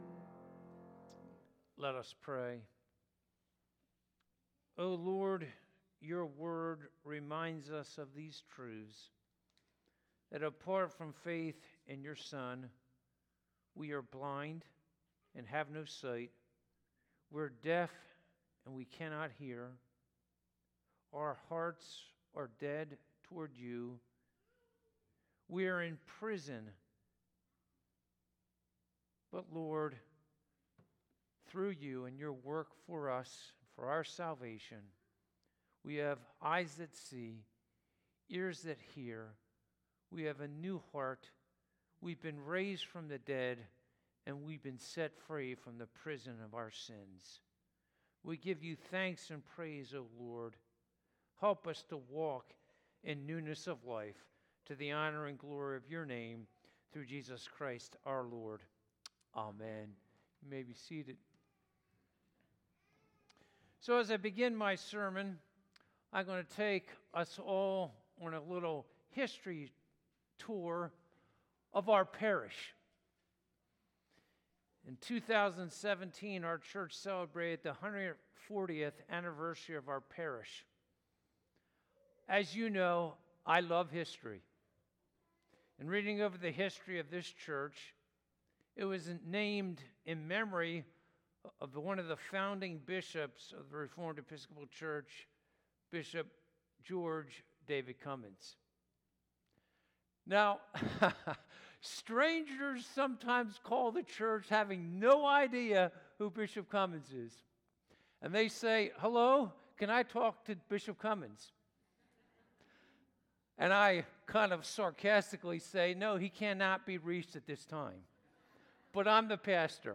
Passage: Isaiah 28:14-22, 1 Peter 2:4-10 Service Type: Sunday Morning « What is the Purpose of This Miracle?